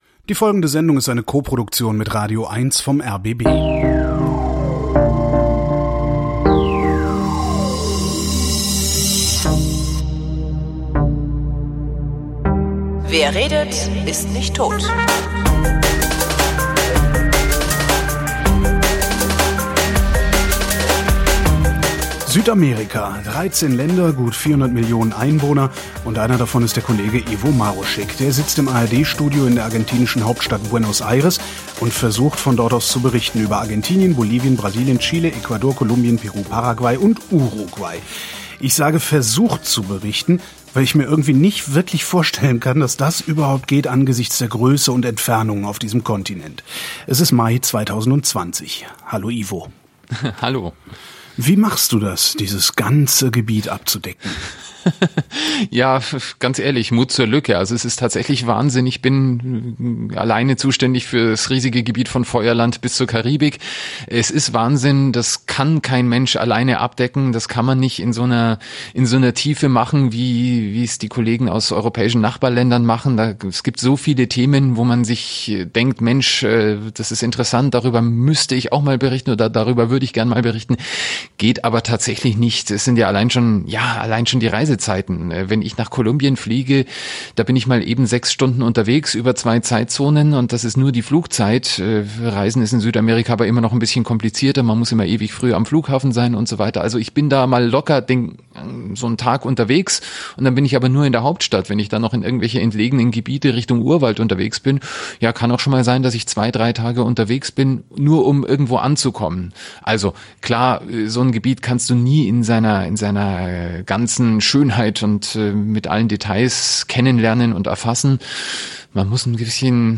Gelegentlich kann ich mich ausgedehnt mit ARD-KorrespondentInnen über deren Berichtsgebiete unterhalten.